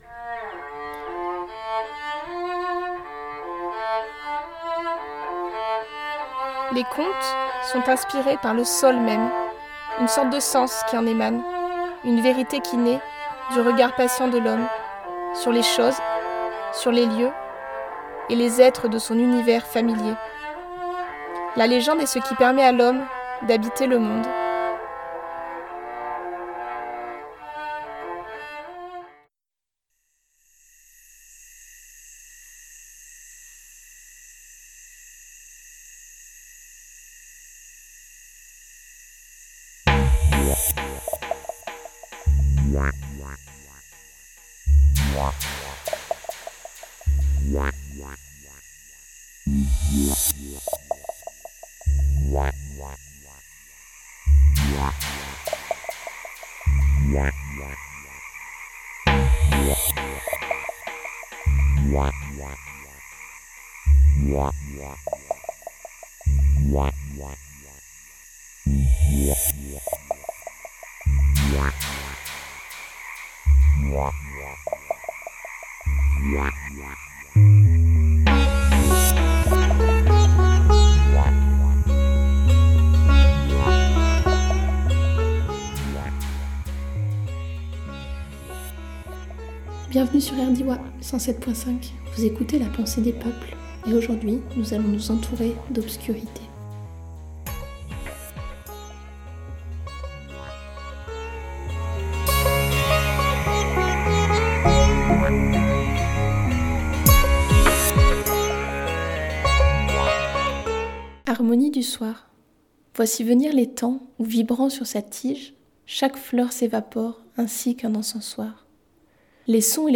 enregistrement de quelques bruits nocturnes